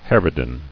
[har·ri·dan]